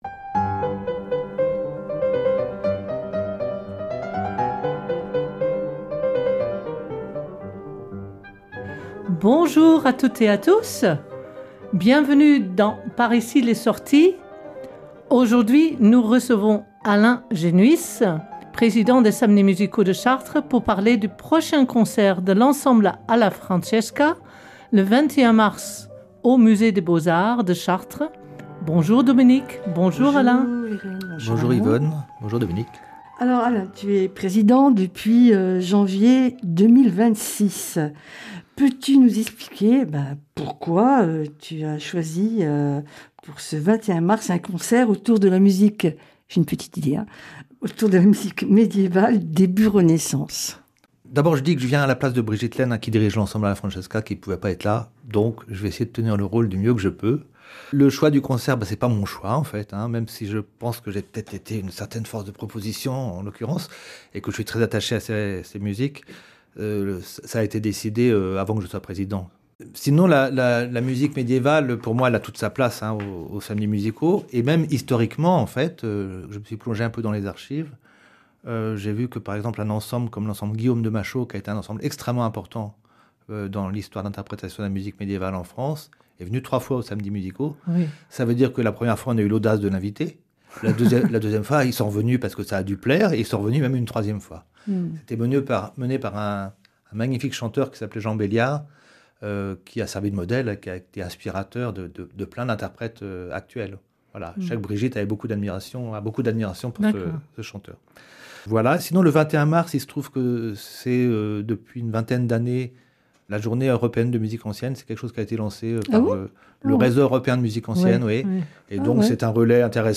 Concerts de Alla francesca : polyphonies du XV° siècle
Alla francesca le 21 mars 2026 à Chartres (deux horaires) : chants et polyphonies du XV° siècle, accompagnés par des instruments médiévaux